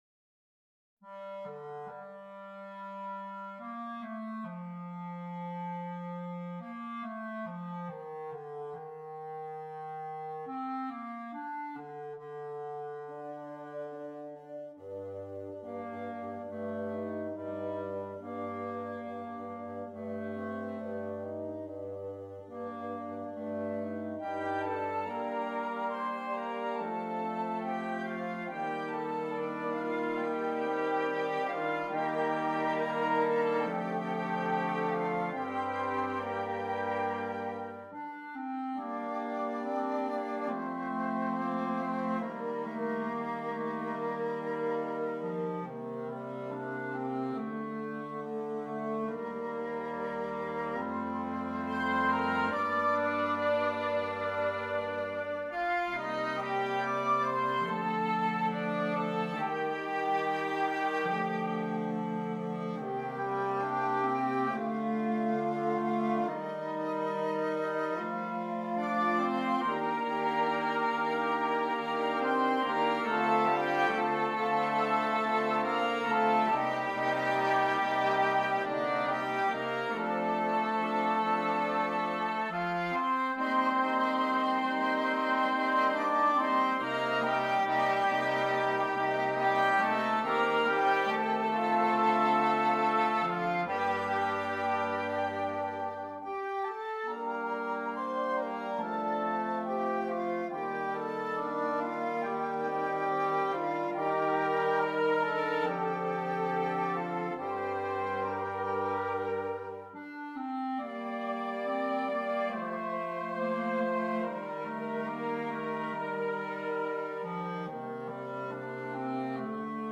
Voicing: Woodwind Quintet